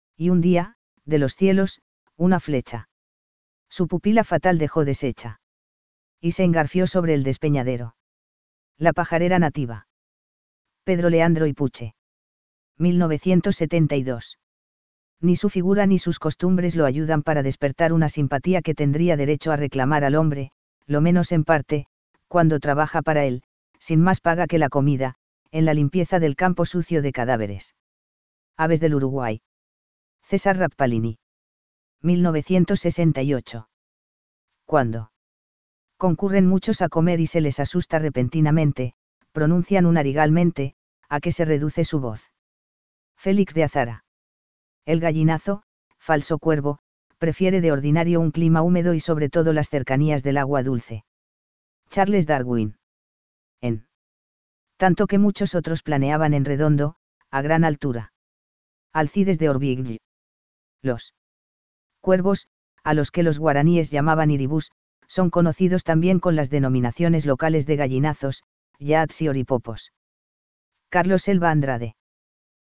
Cathartes aura ruficollis - Cuervo de cabeza roja
Carecen de siringe, por lo cual no emiten vocalizaciones.